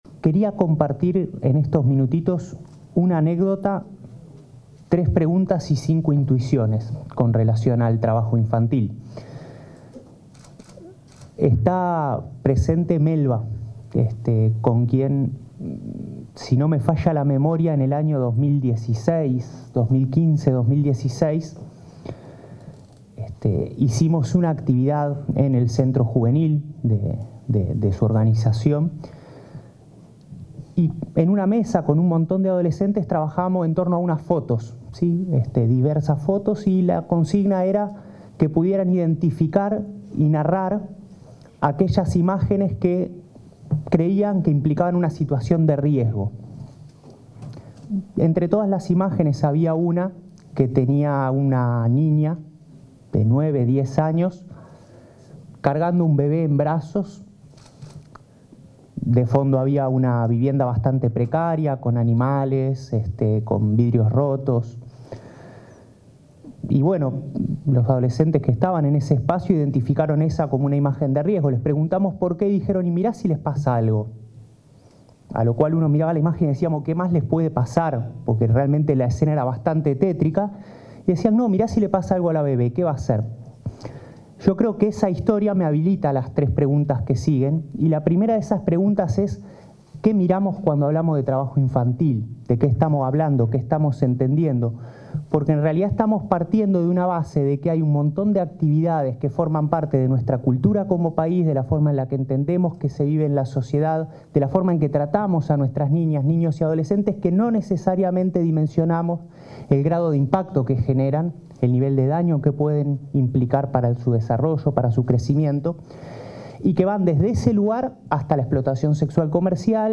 Palabras en jornada de sensibilización sobre trabajo infantil
En una actividad organizada por el Comité Nacional para la Erradicación del Trabajo Infantil, el inspector general del Trabajo y la Seguridad Social,